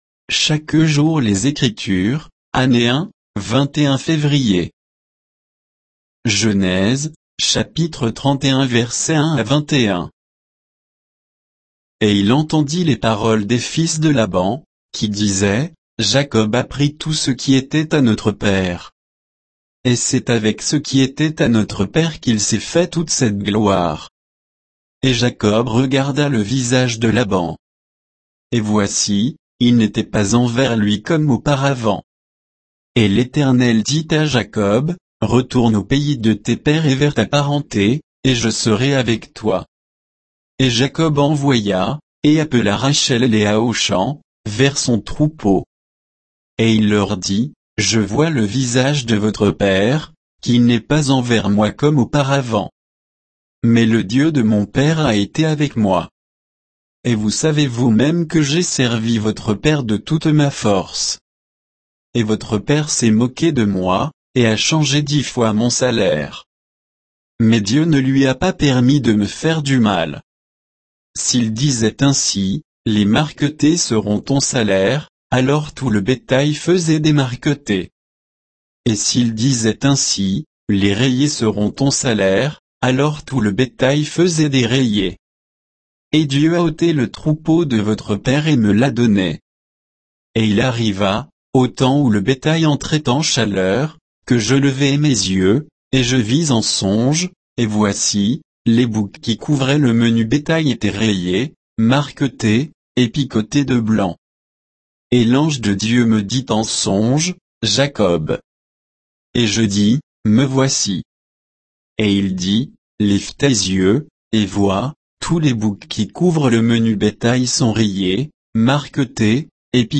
Méditation quoditienne de Chaque jour les Écritures sur Genèse 31, 1 à 21